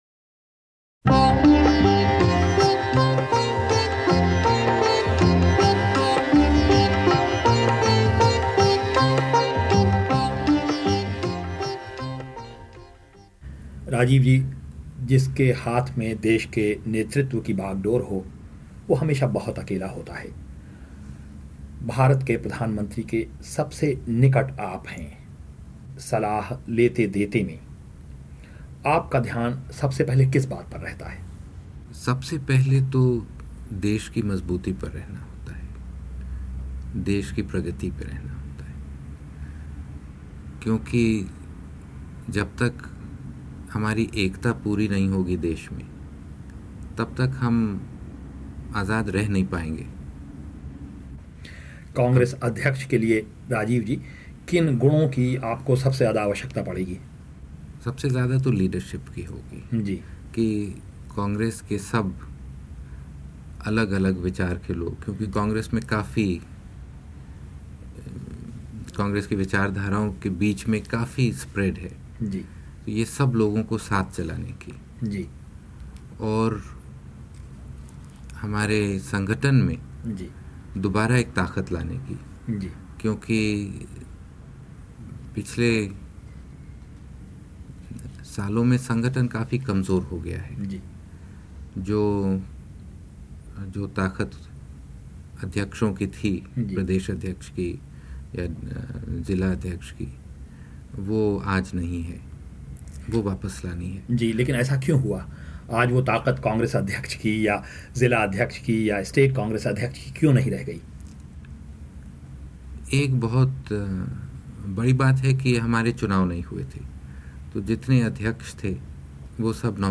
राजीव गांधी का 33 साल पहले बीबीसी को दिया इंटरव्यू